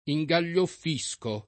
ingaglioffo [ i jg al’l’ 0 ffo ]